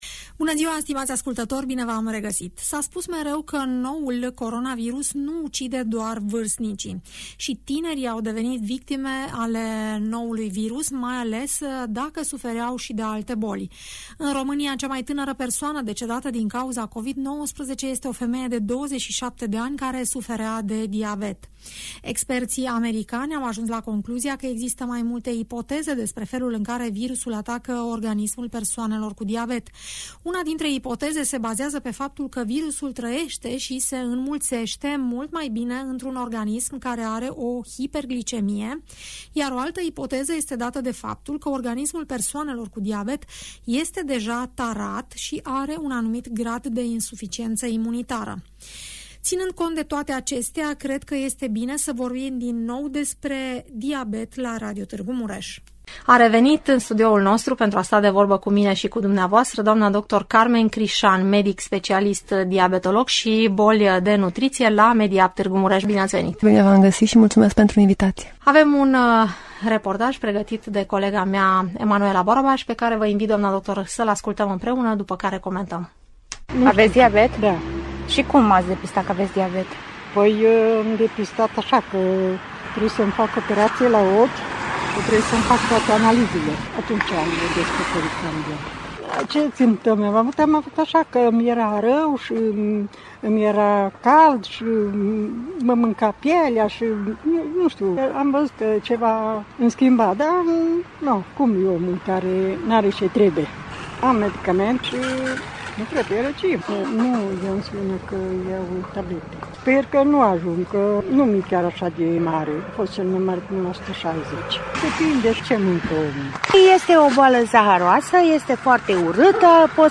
explică în emisiunea „Părerea ta” de la Radio Tg. Mureș, de ce această afecțiune trebuie tratată cu foarte multă seriozitate.